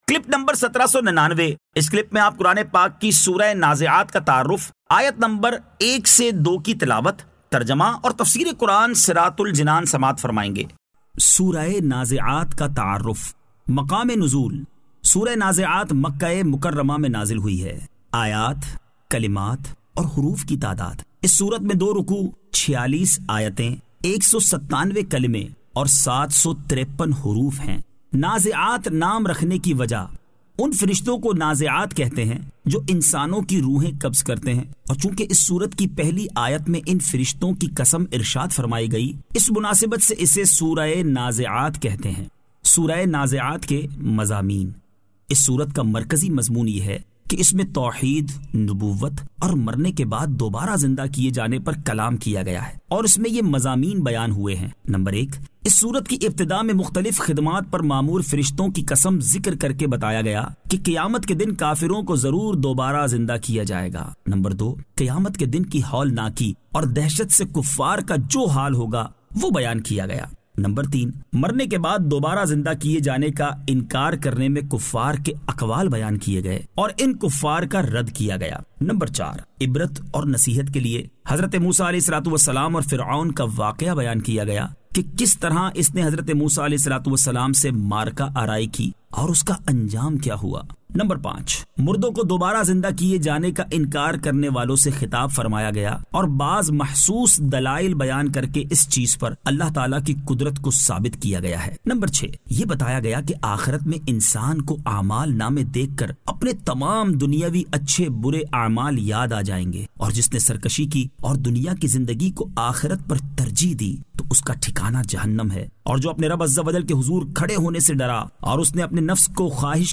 Surah An-Nazi'at 01 To 02 Tilawat , Tarjama , Tafseer